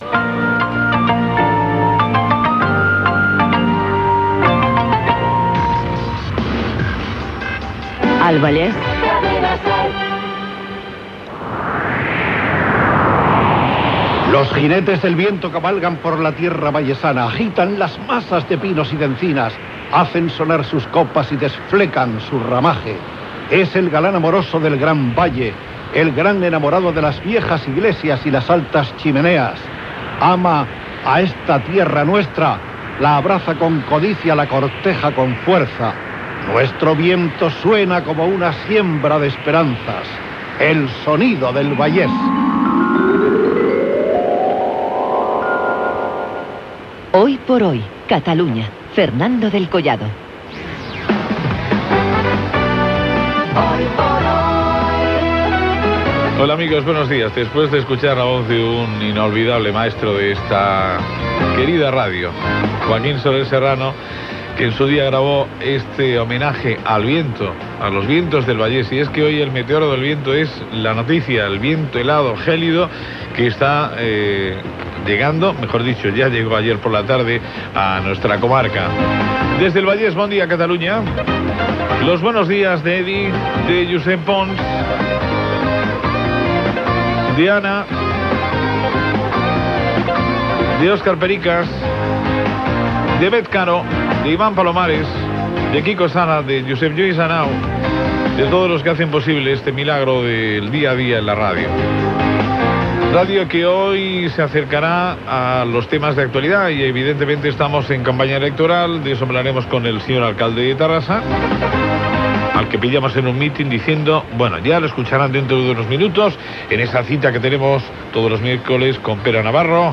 Sortida del butlletí de la Cadena SER. "Homenatge al vent", recitat per Joaquín Soler Serrano. Salutació, equip i sumari del programa.
Indicatiu del programa.